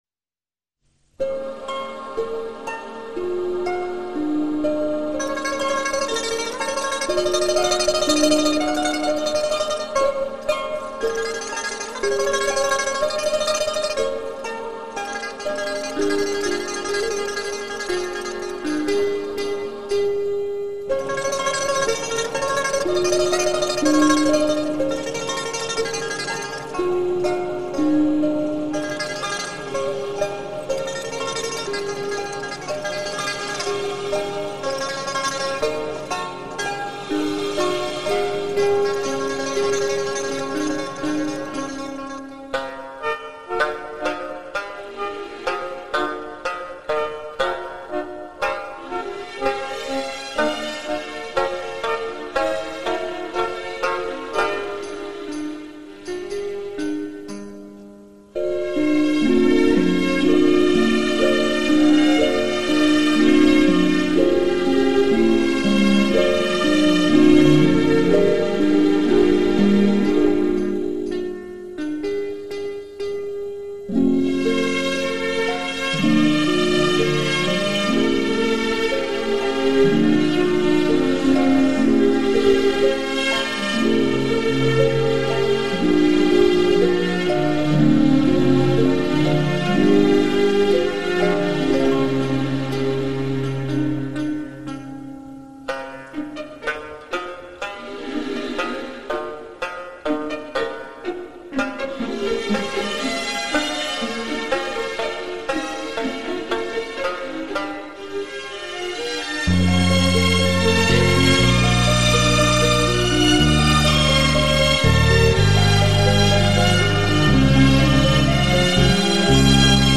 国乐/民族
西藏民歌包括山歌、劳动歌、风俗歌、情歌、嘛呢歌等。山歌高亢悠长、自由跌宕，犹如巍峨绵延的群峰。